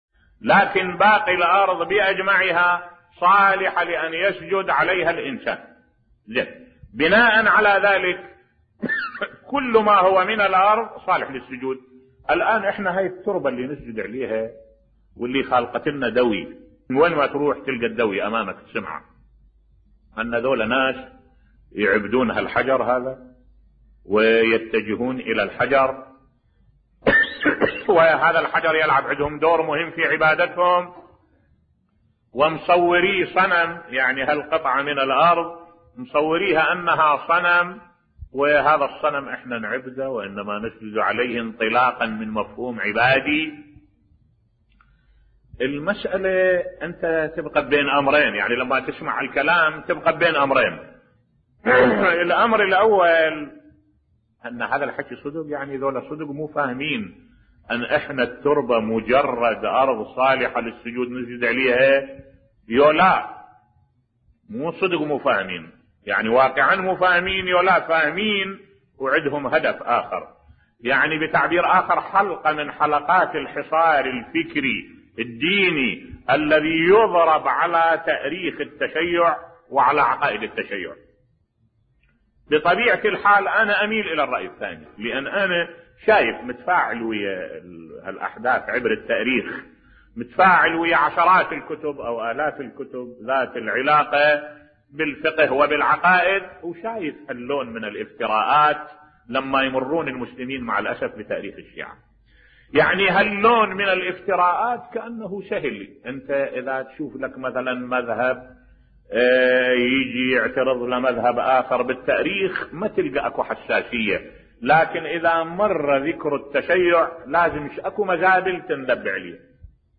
ملف صوتی تضخيم قضية السجود على تربة الحسين (ع) مفتعل وليس حقيقي بصوت الشيخ الدكتور أحمد الوائلي